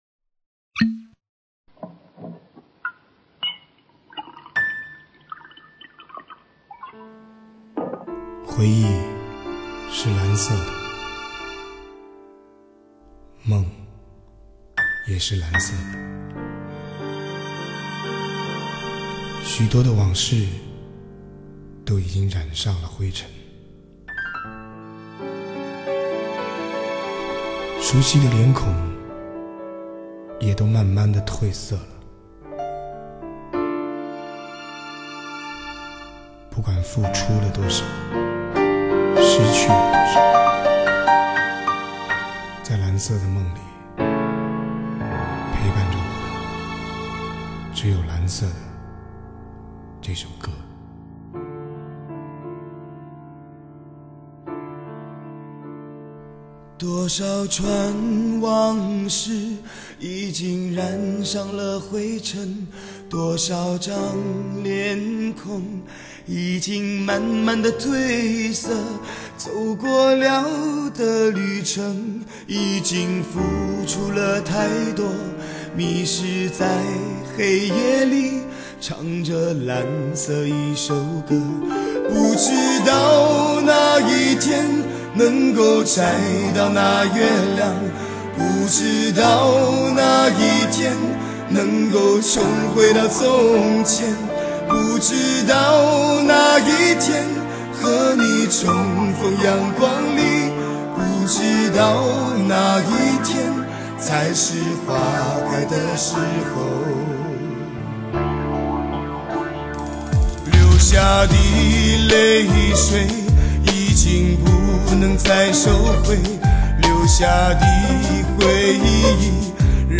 专辑背面的“略带沙哑的声线显得沧桑、磁性、迷人”，确实是对他的最好解读。
更重要的是，他的唱法很自然，不造作，不玩花样。